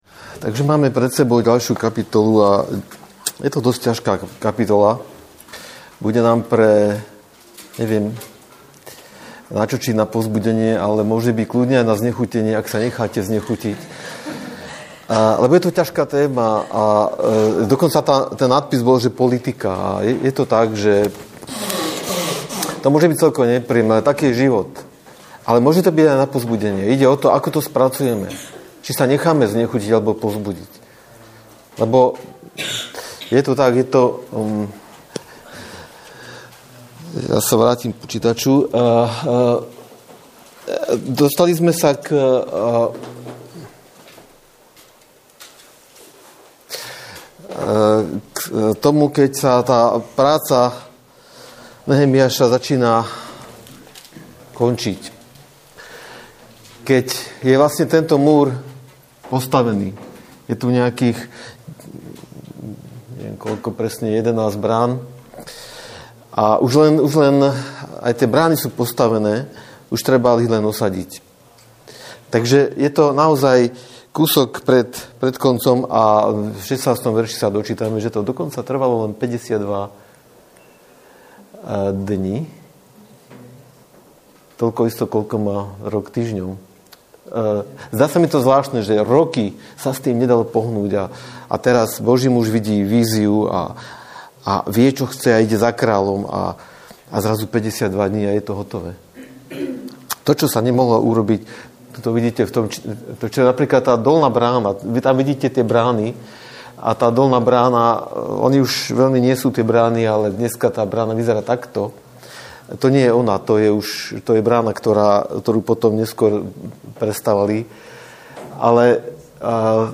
Nahrávka kázne Kresťanského centra Nový začiatok z 18. apríla 2010